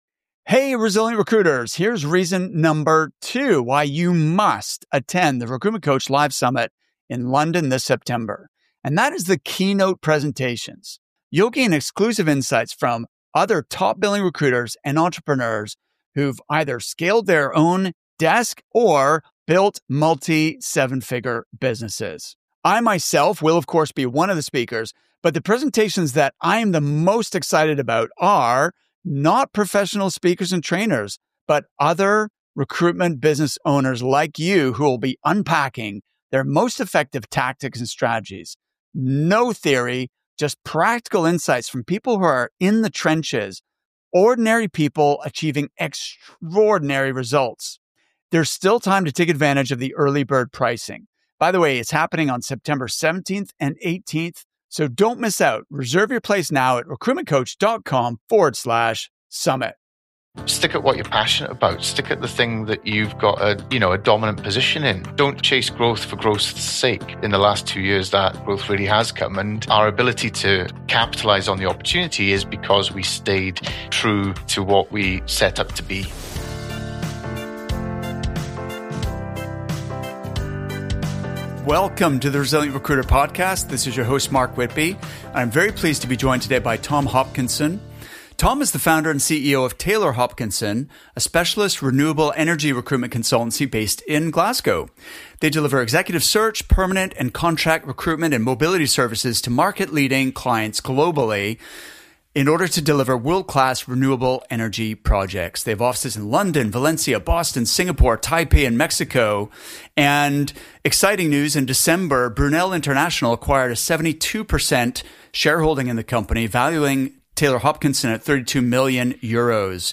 Many founders dream of selling their business one day. In this interview, you’ll hear from someone who’s actually done it.